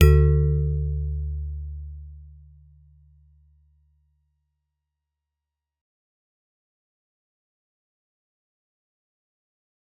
G_Musicbox-D2-f.wav